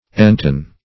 Entune - definition of Entune - synonyms, pronunciation, spelling from Free Dictionary Search Result for " entune" : The Collaborative International Dictionary of English v.0.48: Entune \En*tune"\, v. t. To tune; to intone.